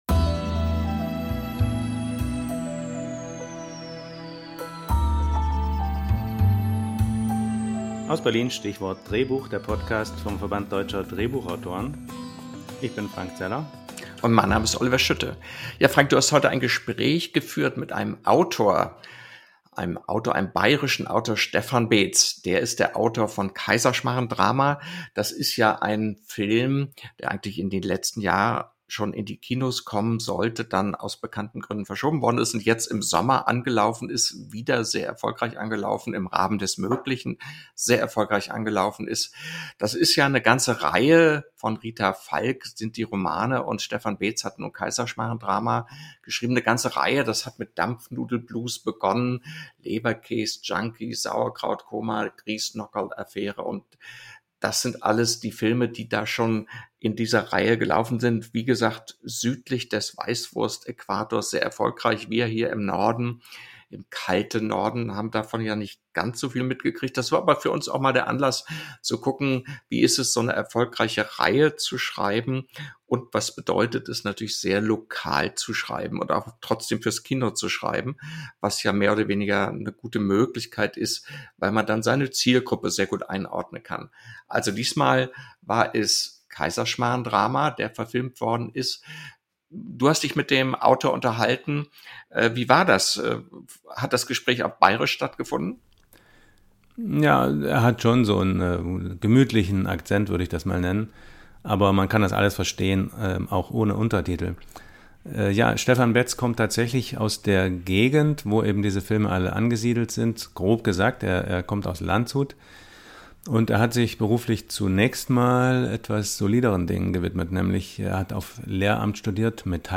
In unserem Gespräch versuchen wir, dem Erfolgsgeheimnis des "lakonischen" bayerischen Humors näher zu kommen.